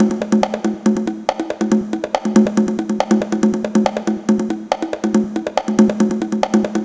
140_bongo_2.wav